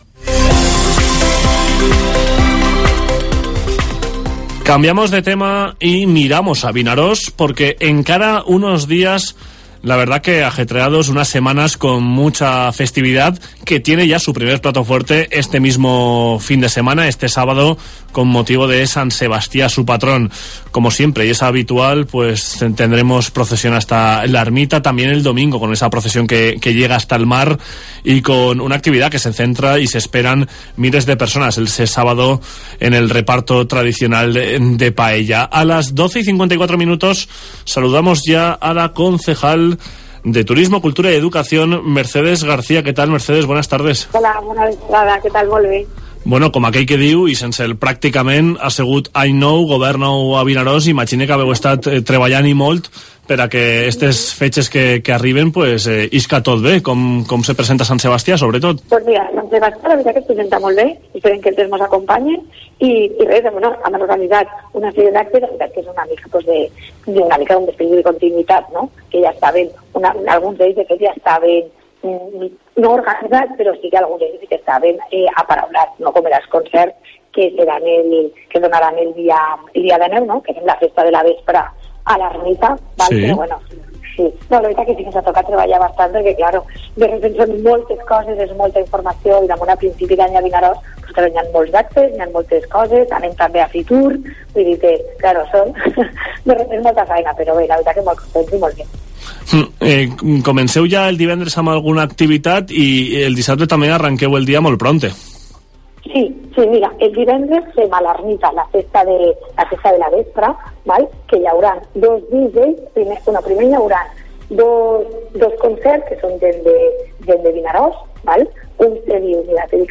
Entrevista a la concejal de Turismo de Vinaròs, Mercedes García